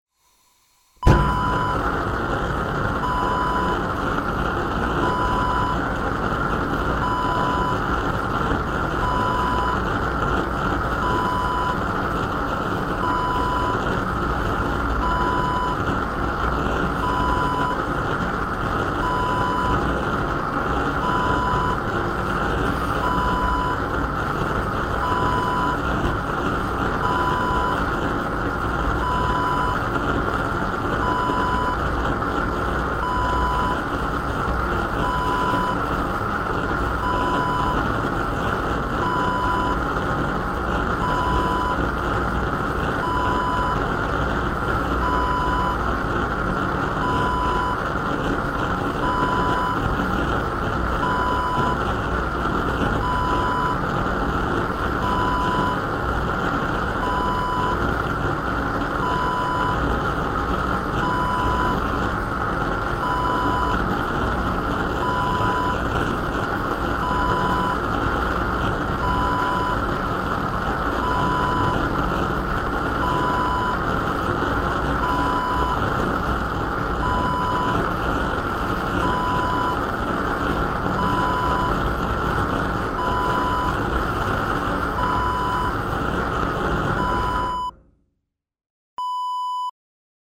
Blow Torch Wav Sound Effect
Description: The sound of a gas blow torch
Properties: 48.000 kHz 24-bit Stereo
A beep sound is embedded in the audio preview file but it is not present in the high resolution downloadable wav file.
Keywords: blow torch, blowtorch, blow-torch, torch, flame, flamegun, gas, fire, burn, burning
blow-torch-preview-1.mp3